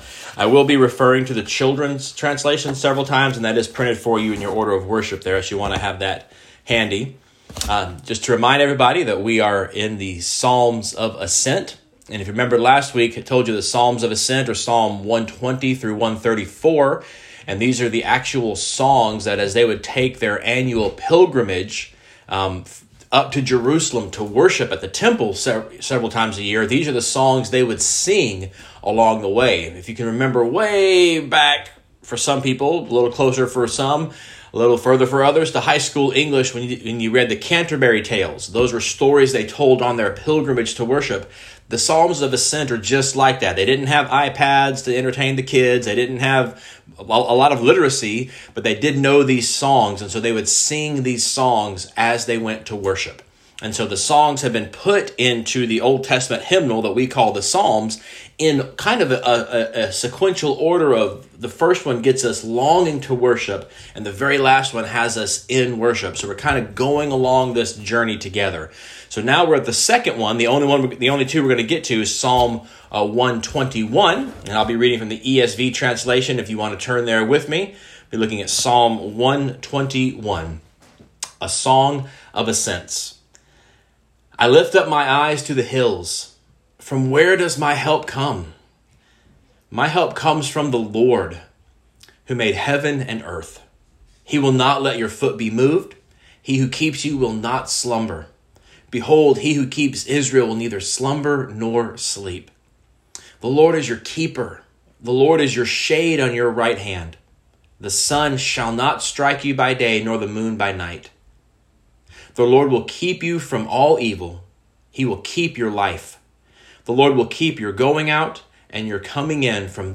June 13, 2020 Guest Speaker Sermons series Weekly Sunday Service Save/Download this sermon Psalm 121 Other sermons from Psalm My Help Comes from the Lord A Song of Ascents. 121:1 I […]